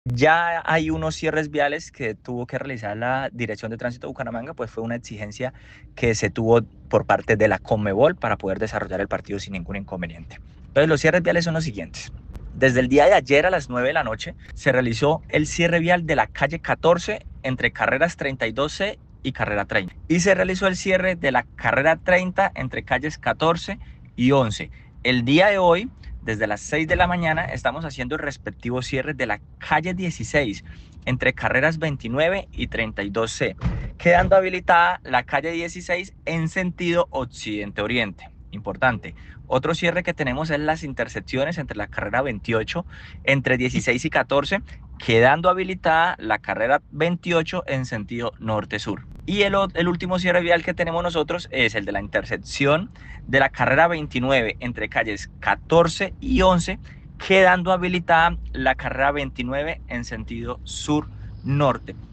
Escuche aquí a Jhair Manrique, Director de Tránsito de Bucaramanga: